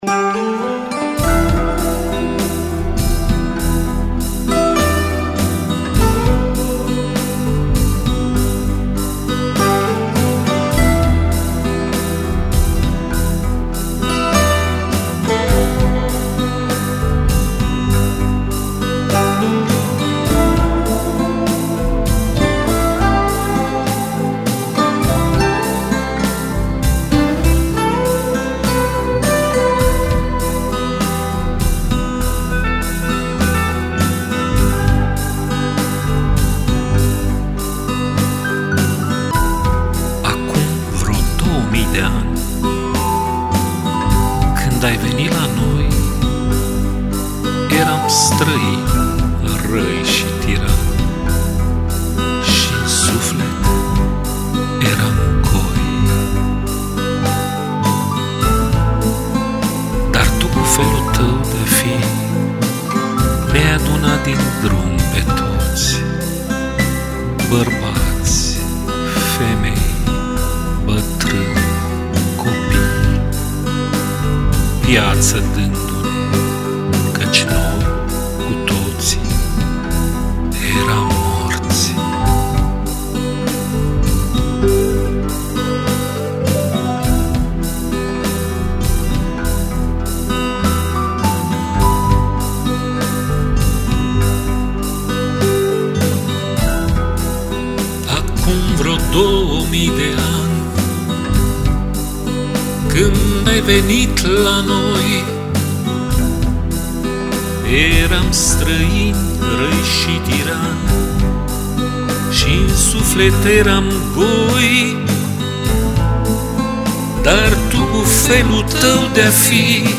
Poezia: